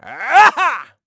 Riheet-Vox_Attack7_kr.wav